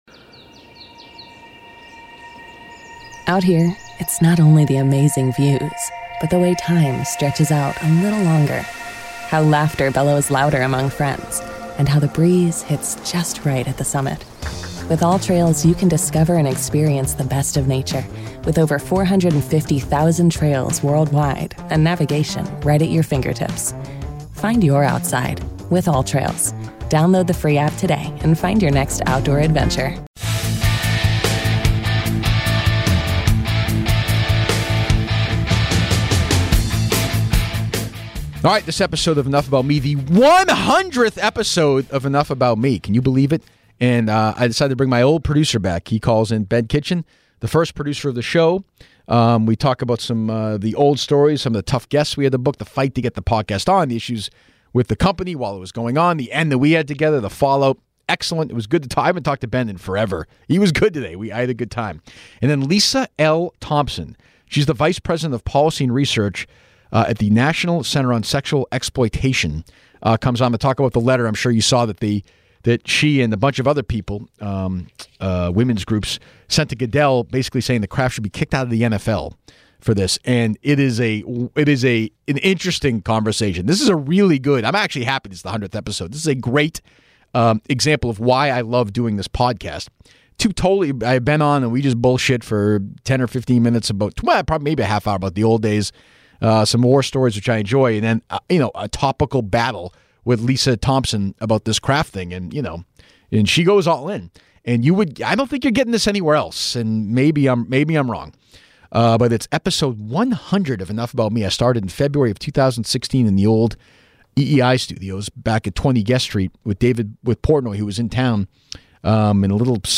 Double interview!